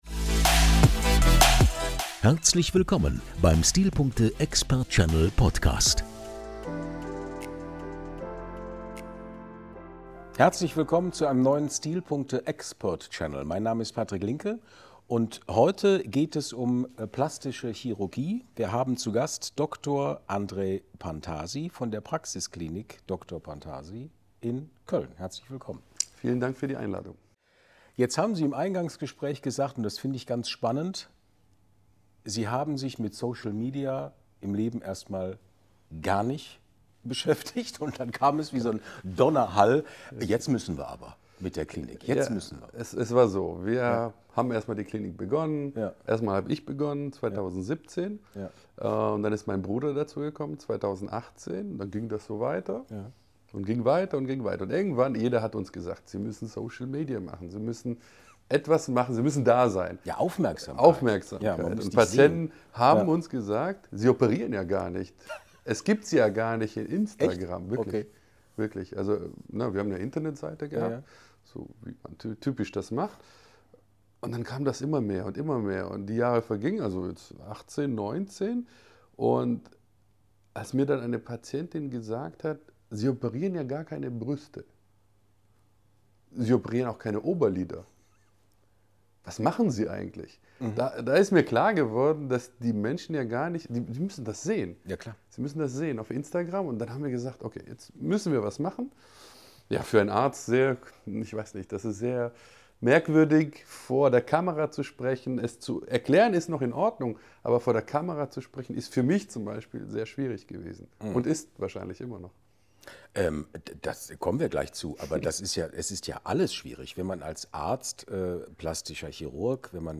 Ein Gespräch voller wertvoller Einblicke in die Realität der plastischen Chirurgie – ehrlich, informativ und inspirierend.